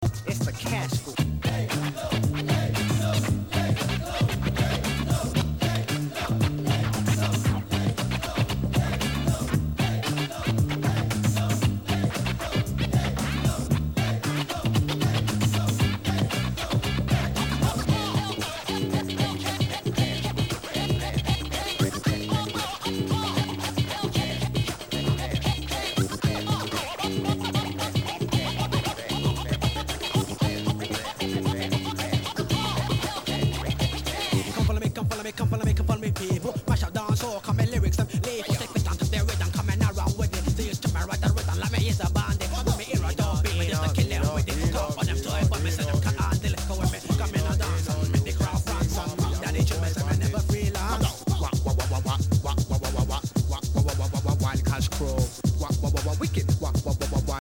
HIP HOP/R&B
終盤はラガMCも絡む1989年 UKミドル!!